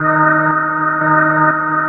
87 DRONE  -R.wav